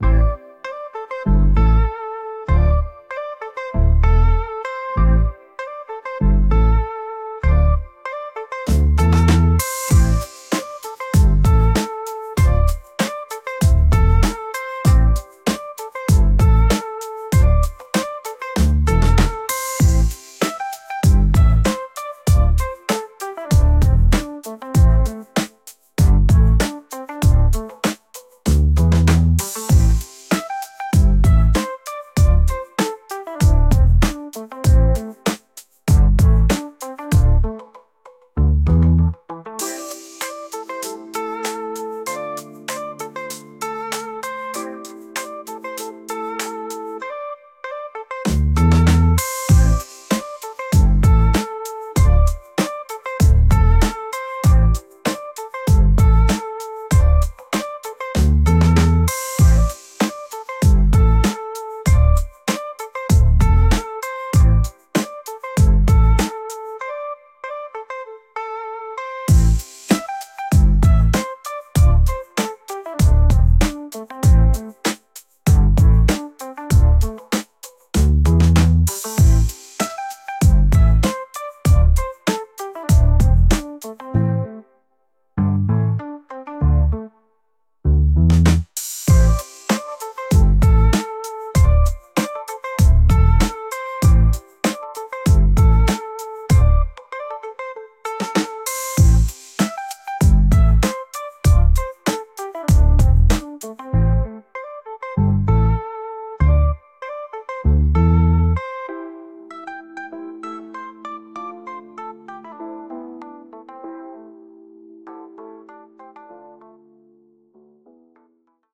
RnB
Happy, Groovy
96 BPM